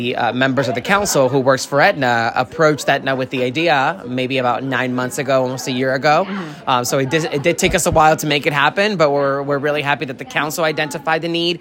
The Chevy Chase Community Center held a ceremony this morning highlighting the donation of a food delivery van.